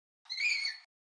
Звуки красного волка
Вскрикивает красный волк